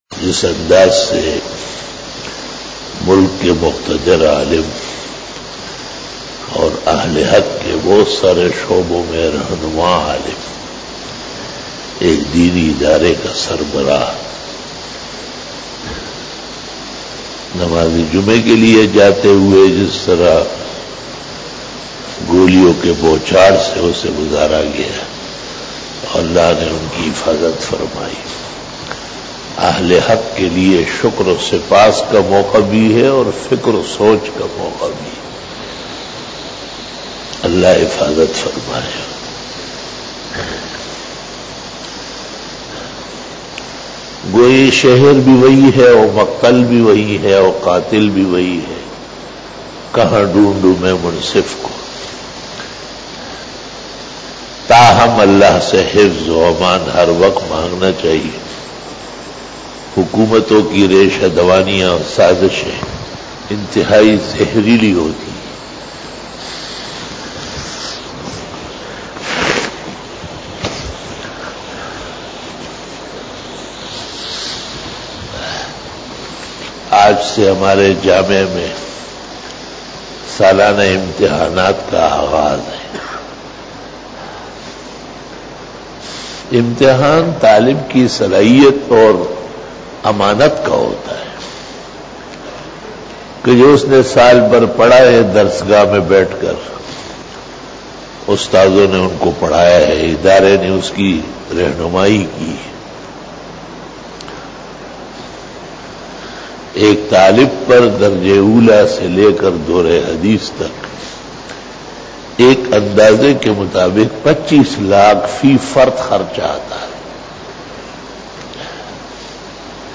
After Namaz Bayan
After Fajar Byan